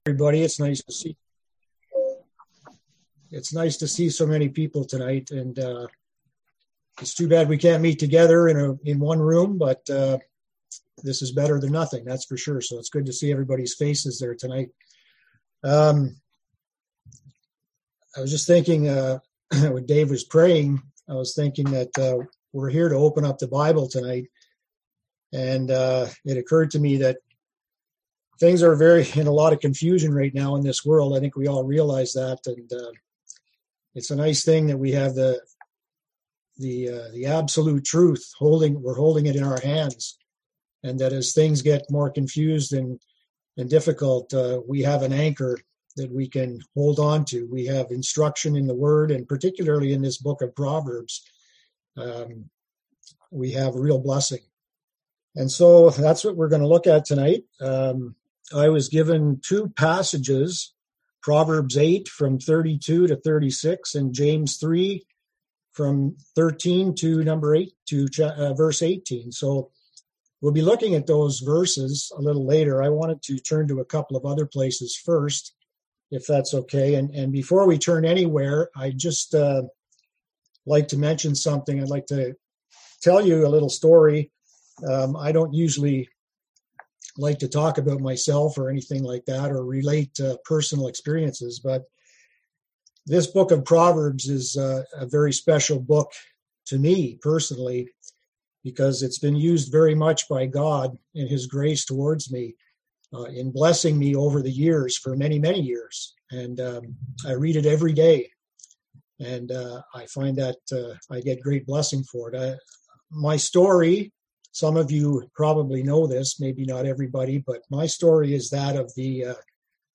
Wisdom Passage: Proverbs 3:13-18, Proverbs 8:32-36, James 3:13-18 Service Type: Seminar